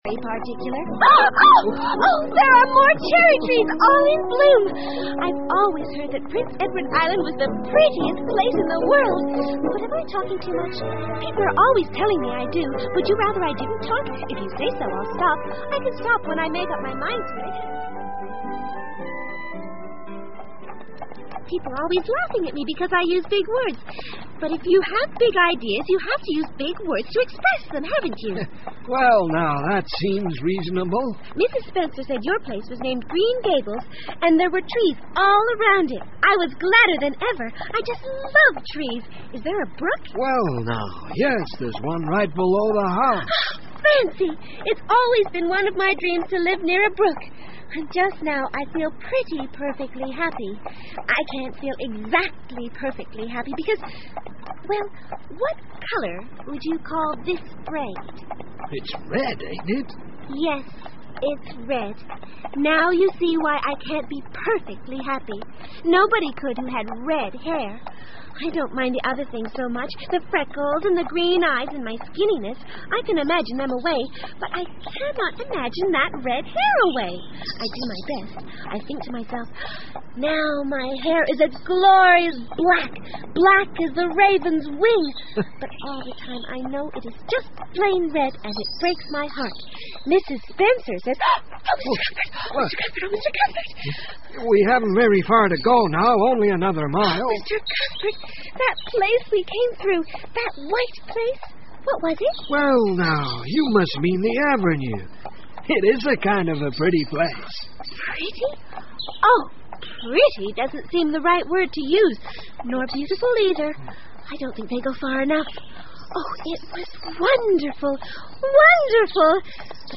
绿山墙的安妮 Anne of Green Gables 儿童广播剧 2 听力文件下载—在线英语听力室